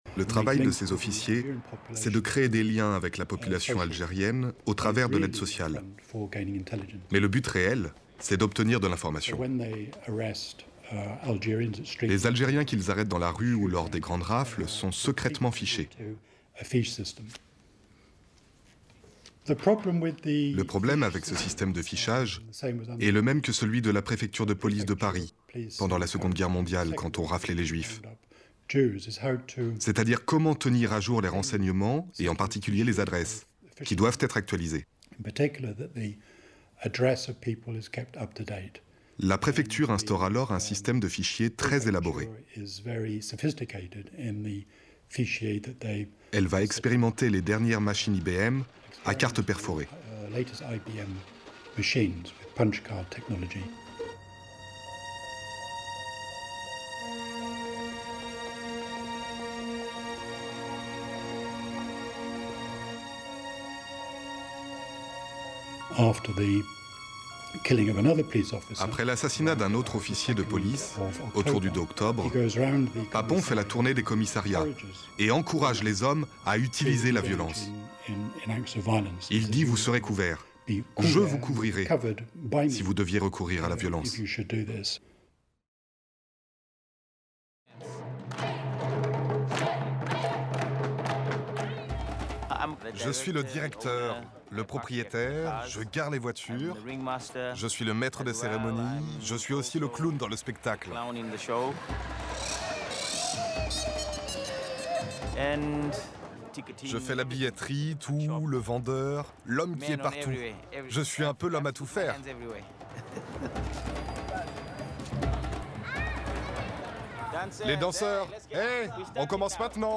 Bandes-son